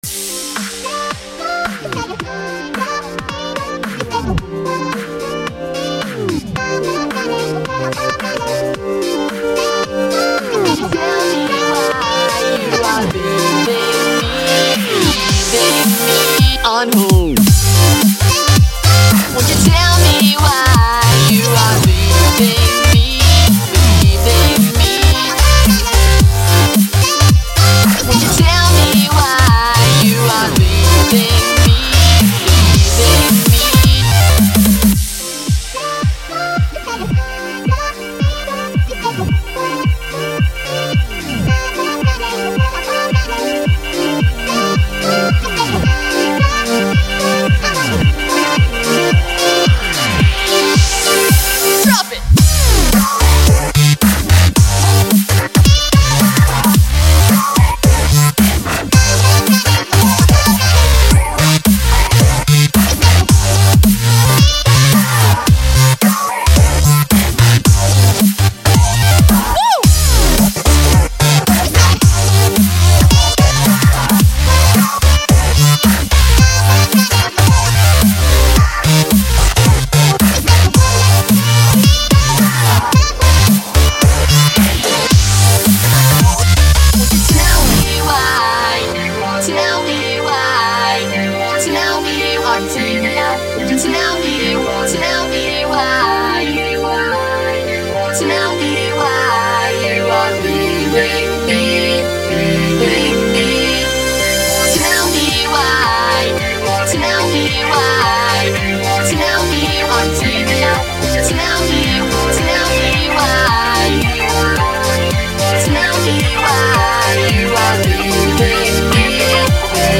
A double VIP remix, as it were.